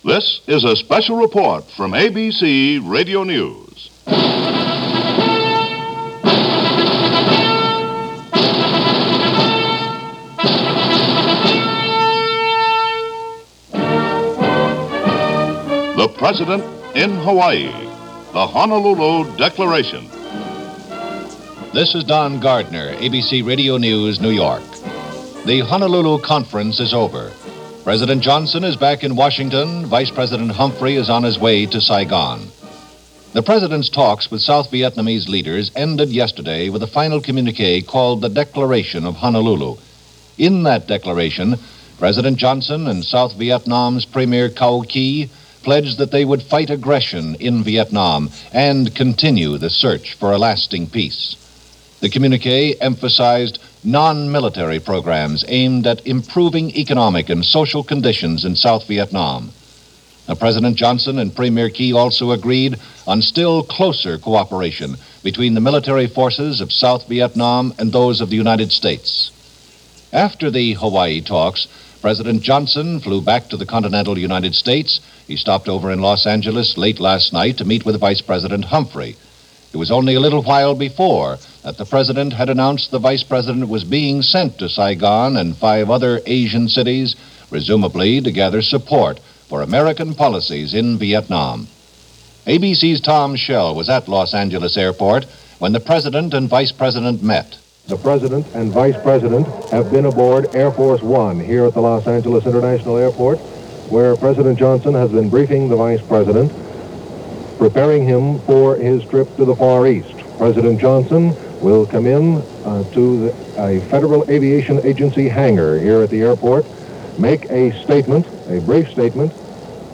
LBJ - Honolulu Declaration - February 8, 1966 - ABC Radio News Special on the just-concluded Diplomatic talks on the war in Vietnam.
ABC-LBJ-Honolulu-Conference-February-8-1966.mp3